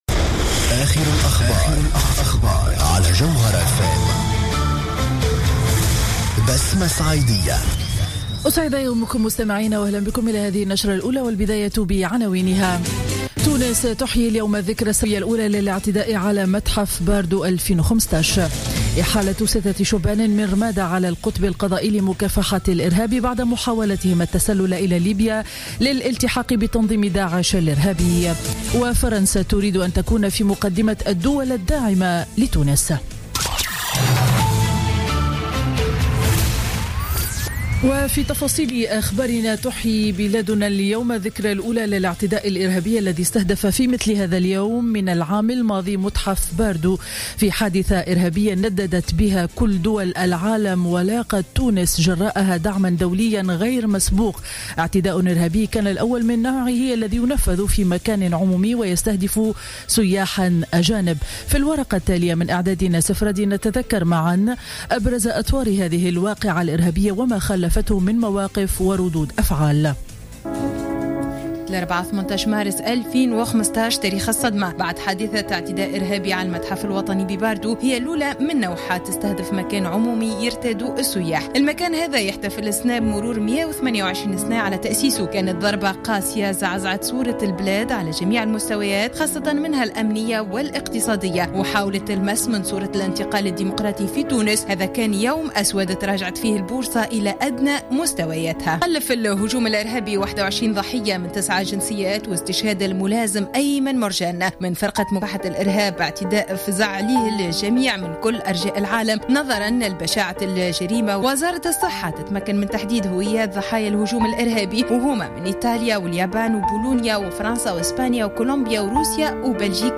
نشرة أخبار السابعة صباحا ليوم الجمعة 18 مارس 2016